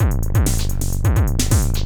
drums01.wav